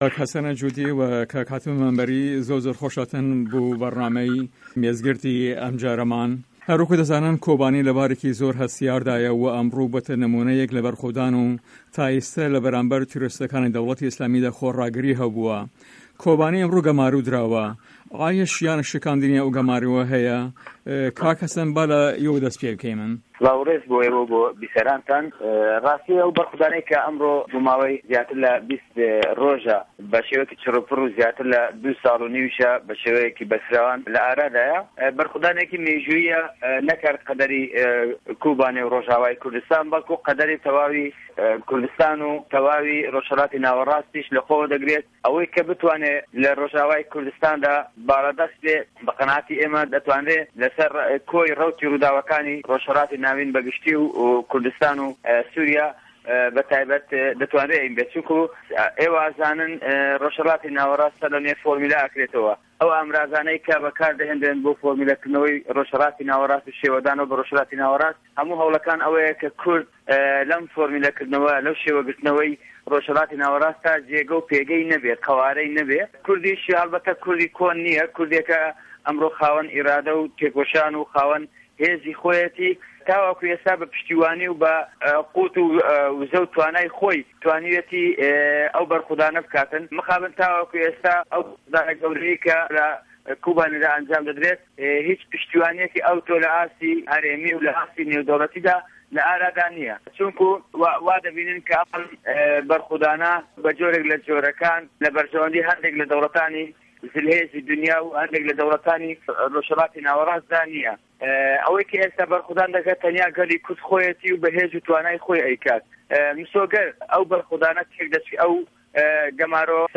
مێز گرد: کۆبانی، داعش، تورکیا و خۆراگری کورد له‌ رۆژئاوا